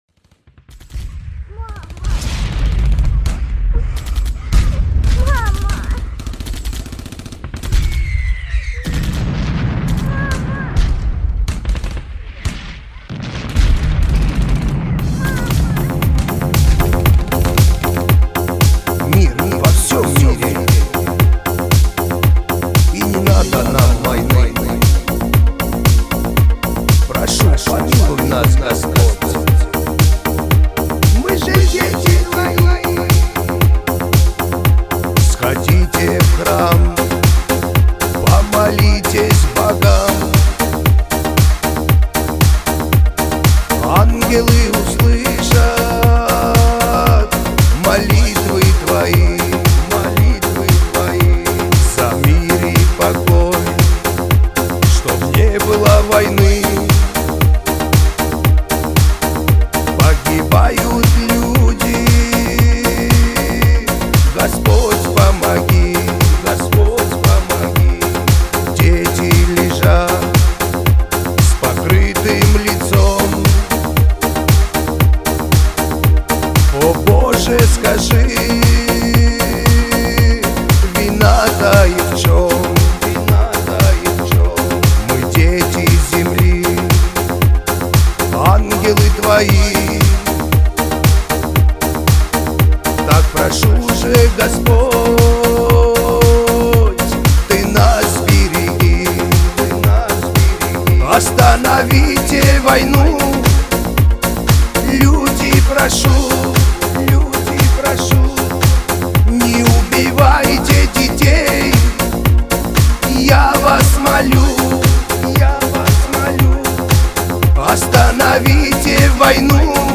Скачать музыку / Музон / Музыка Шансон